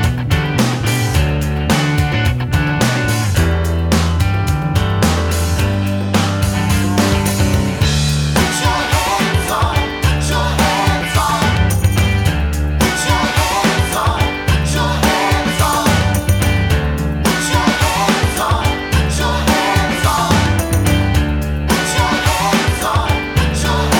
no Backing Vocals Indie / Alternative 3:39 Buy £1.50